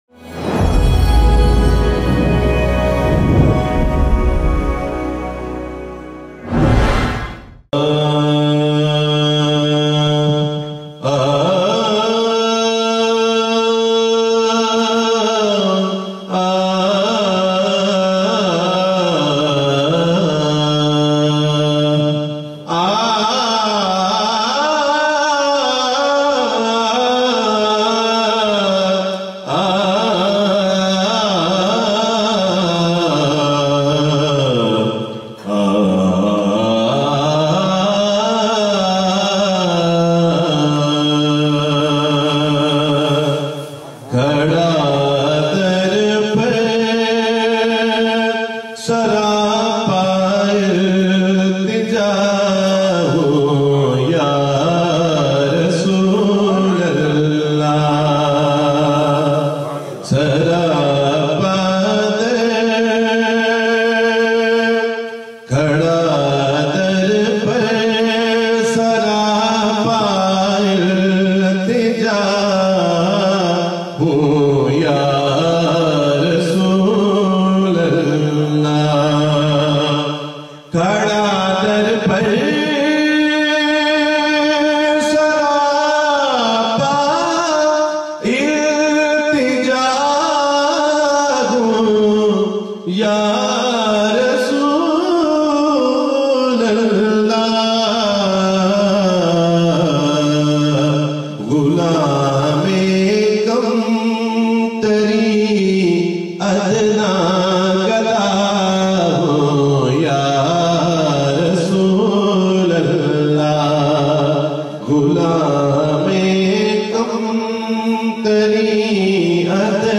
naat shareef in the beautiful voice